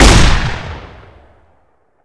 Wpn_riotshotgun_fire_2d_01.ogg